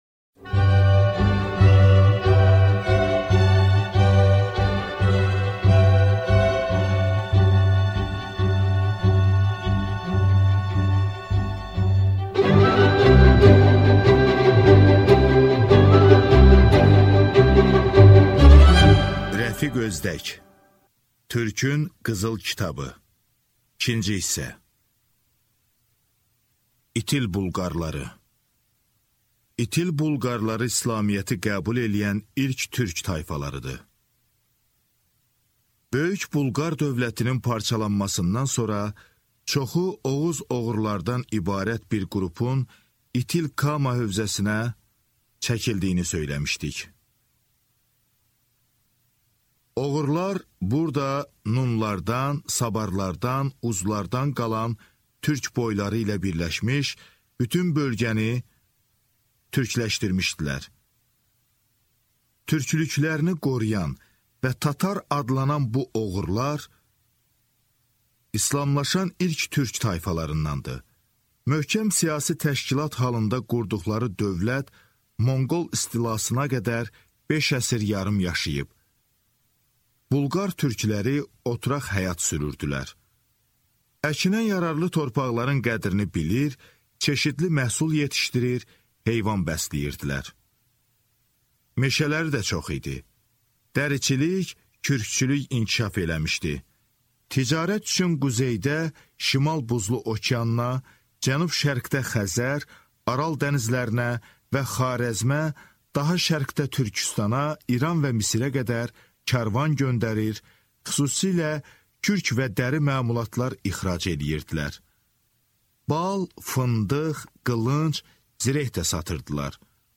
Аудиокнига Türkün qızıl kitabı 2-ci kitab | Библиотека аудиокниг